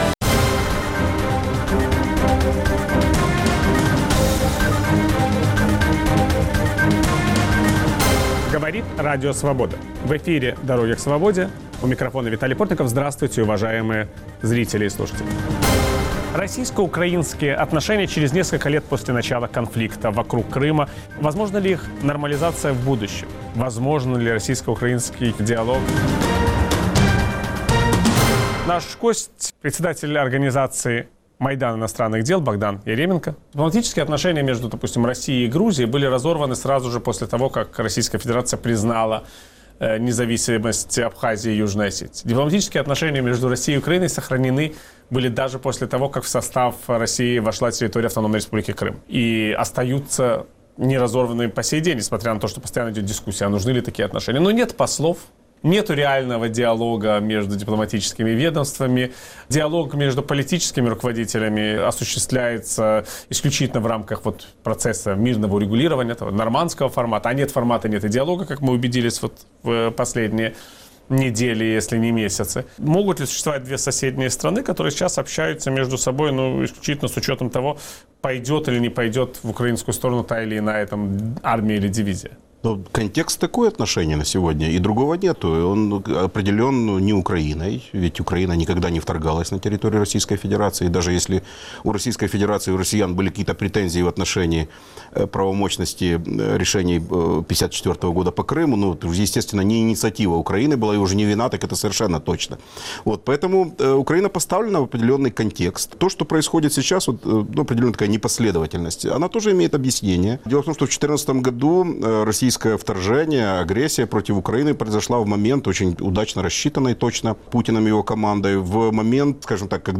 Российско-украинский конфликт и дипломатия. Собеседник Виталия Портникова - глава организации "Майдан иностранных дел" Богдан Яременко